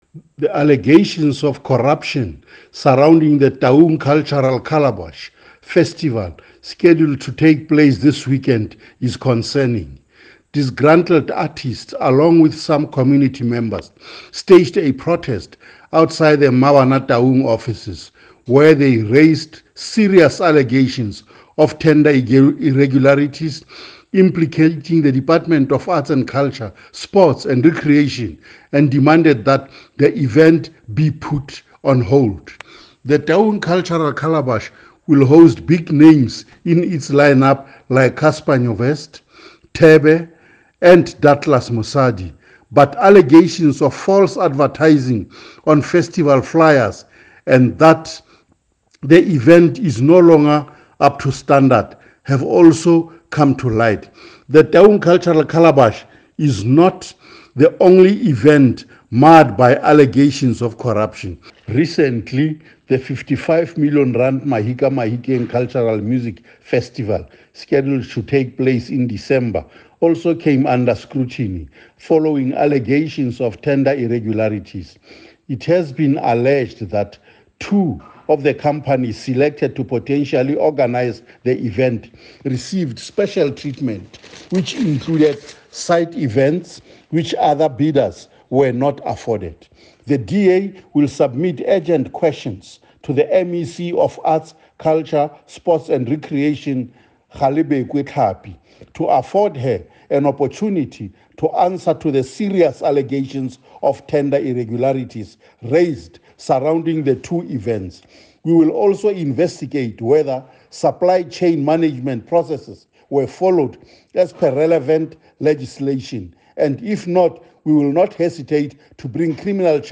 Note to Editors: Please find attached soundbites in
Setswana by Winston Rabotapi MPL.